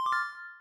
get-key.ogg